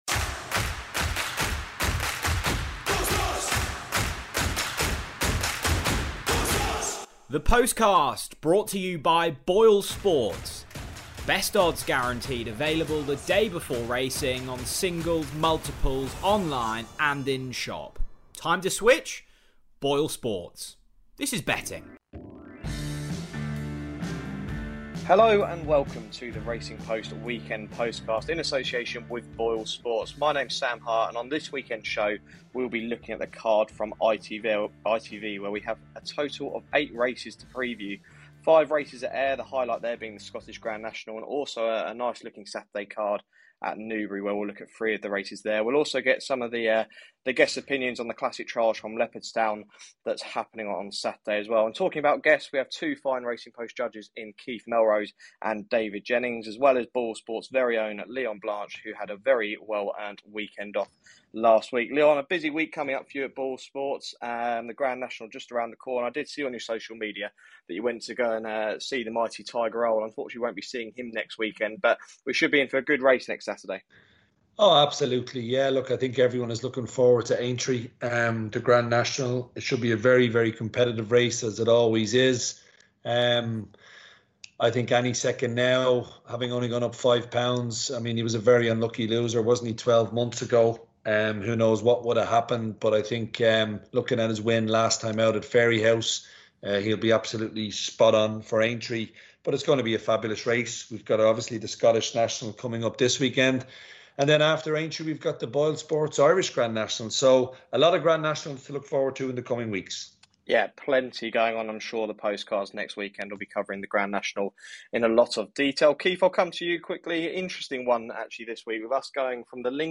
The panel take a look at the televised ITV action on Saturday where action from Ayr takes centre stage and there's also racing from Newbury and Leopardstown.